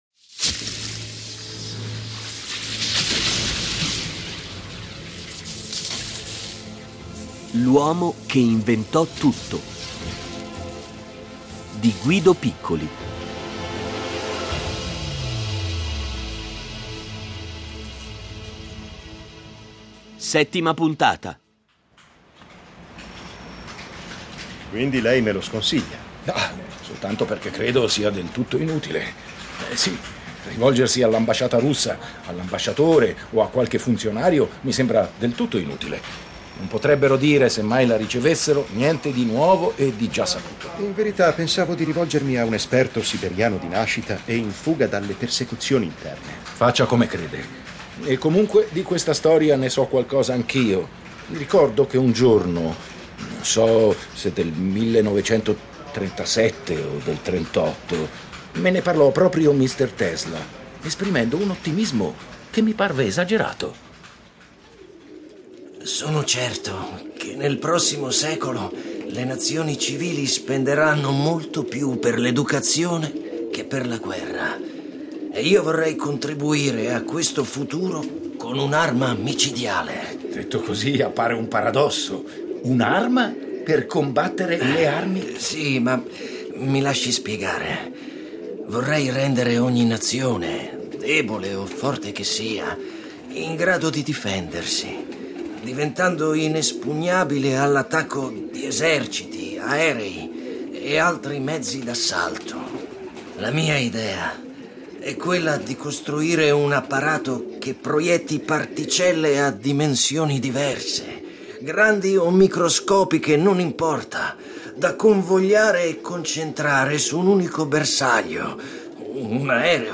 Originale radiofonico